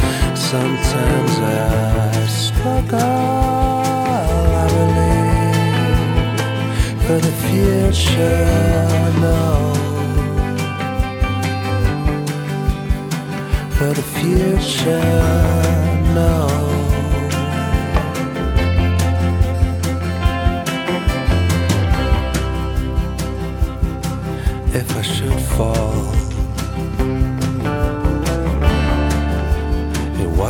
enregistré et mixé en Suède